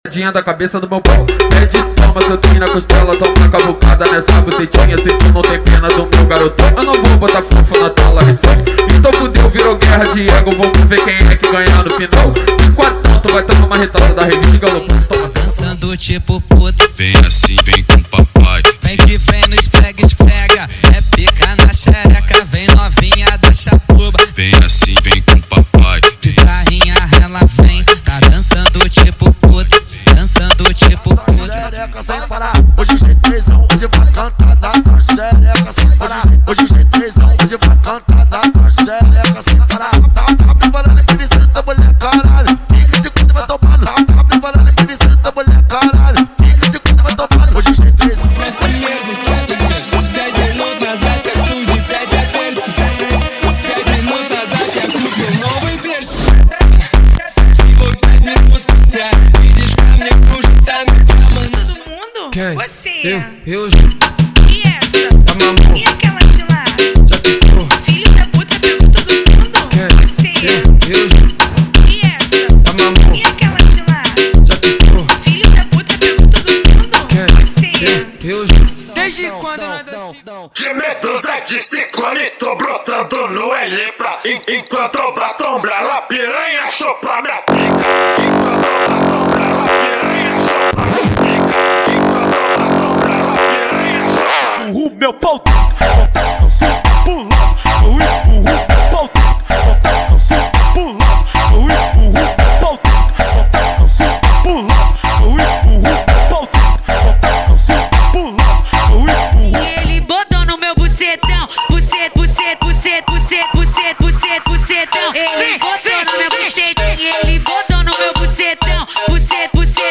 • Funk Proibidão + mandelão = 100 Músicas
• Sem Vinhetas
• Em Alta Qualidade